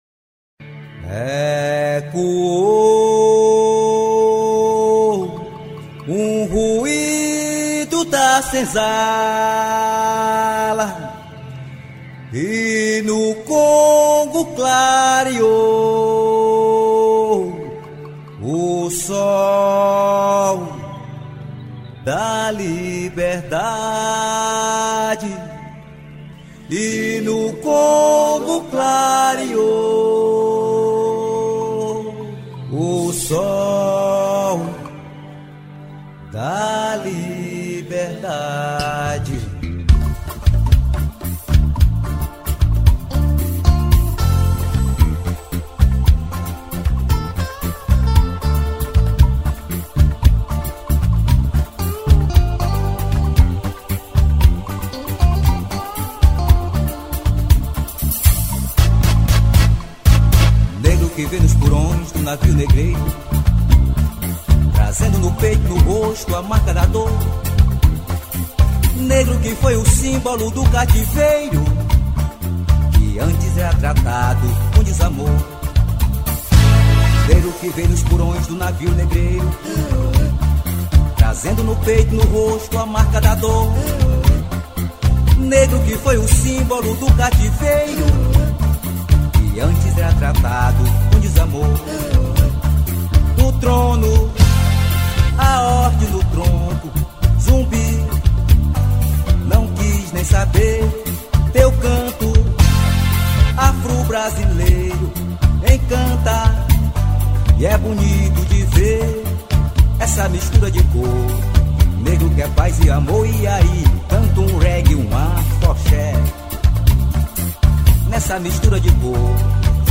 2631   04:26:00   Faixa: 4    Rock Nacional